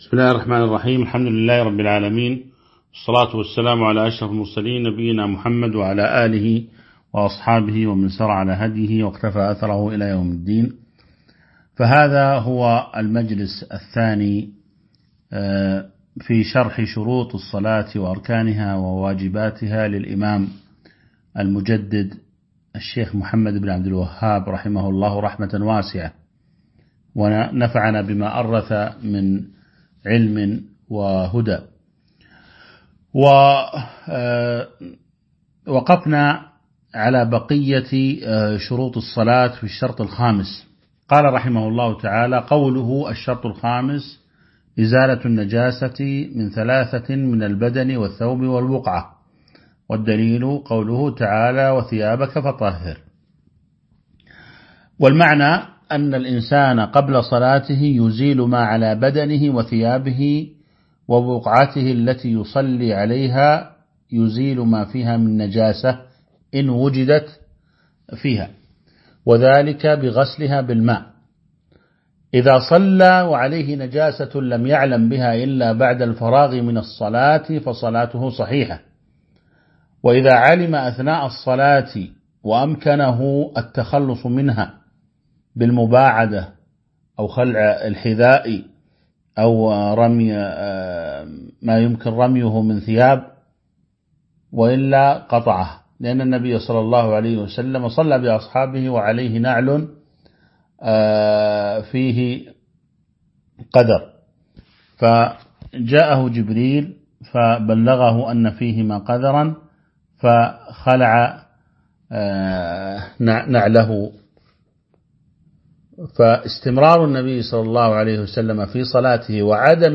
تاريخ النشر ٢٩ شوال ١٤٤٢ هـ المكان: المسجد النبوي الشيخ